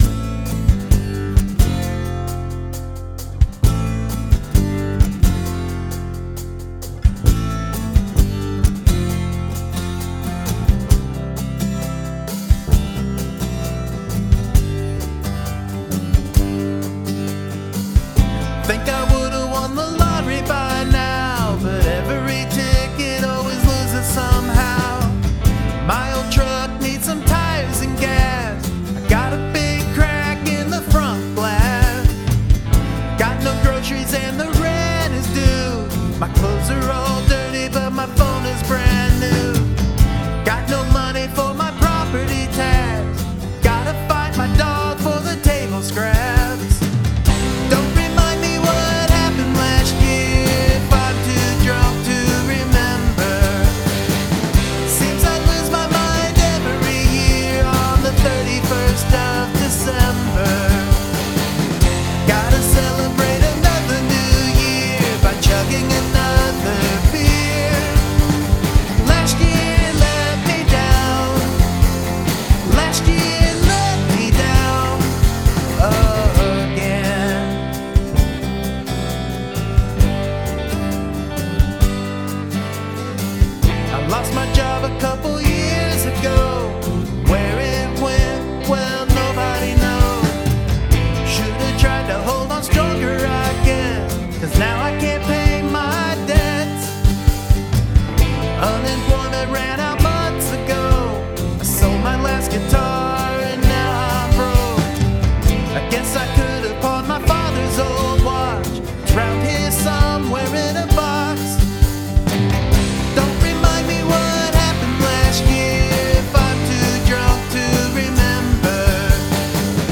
The chorus is catchy, and the drums are excellent. A super acoustic and vocal driven song!
This sounds so smooth. Those acoustic guitars sounds fantastic!
Great rocking song with an alt country vibe.